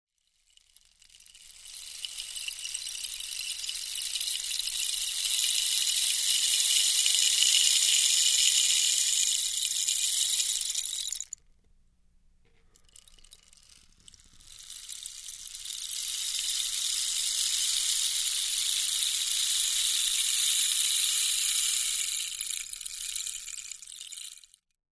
Cactus Rain Stick
• Produces the sounds of a showering rainfall
Made from real dried cactus and filled with Acadian rock, these shakers feature smooth edges and an initial application of natural linseed oil.
3914_Sound-Clip-Cactus-Rain-Stick-Swish.mp3